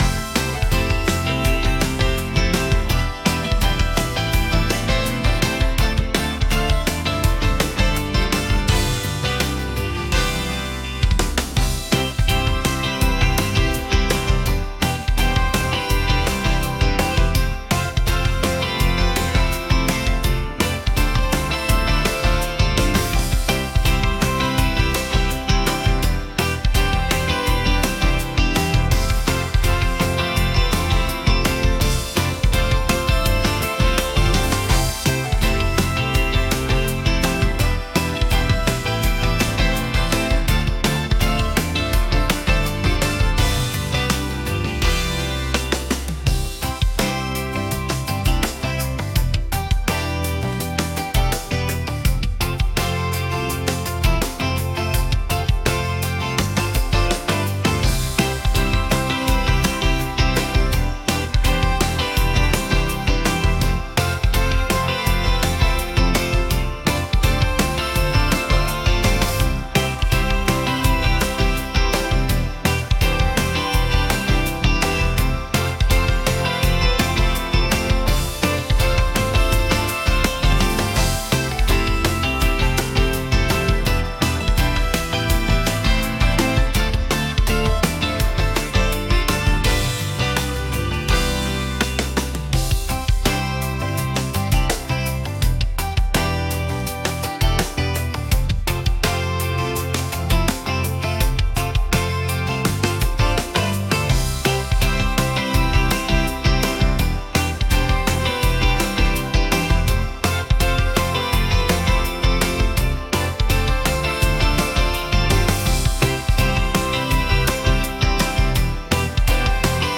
明るい